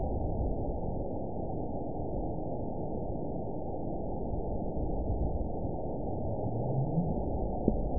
event 917884 date 04/19/23 time 23:00:54 GMT (2 years ago) score 8.14 location TSS-AB04 detected by nrw target species NRW annotations +NRW Spectrogram: Frequency (kHz) vs. Time (s) audio not available .wav